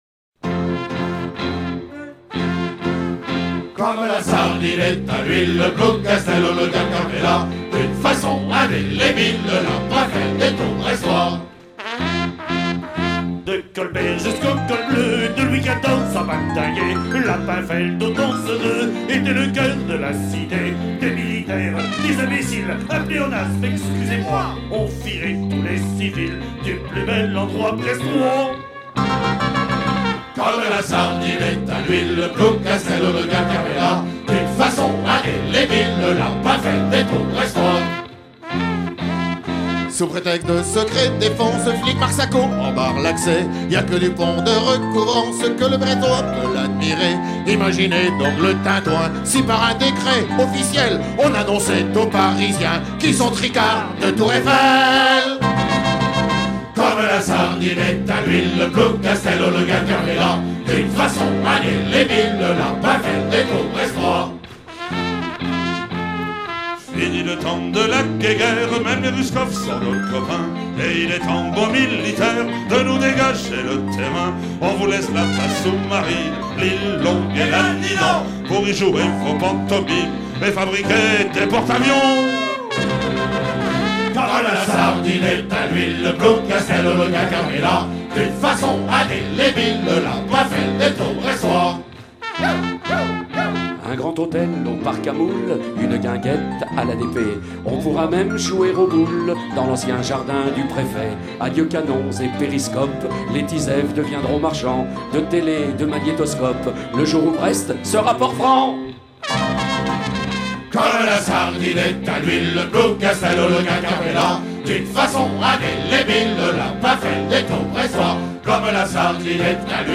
Chant enregistré lors de Paimpol 99
Pièce musicale éditée